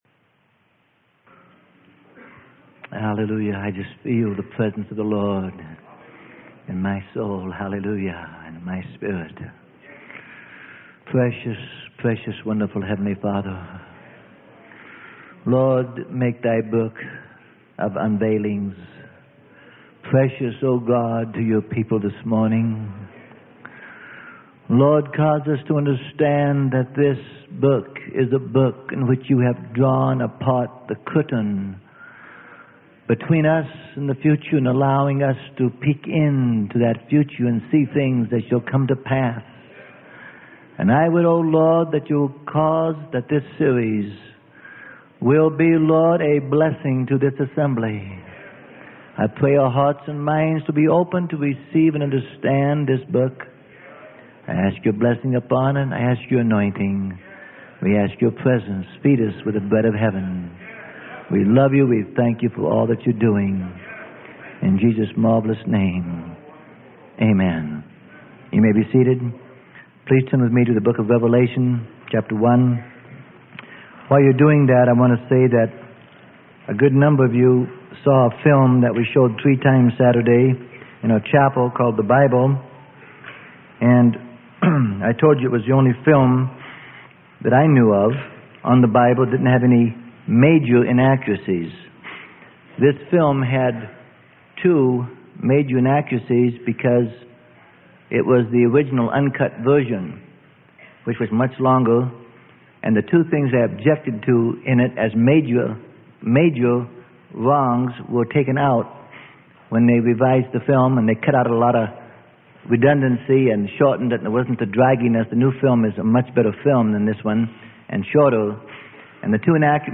Sermon: Golden Nuggets From the Book of Revealings - Part 1 and 2 - Freely Given Online Library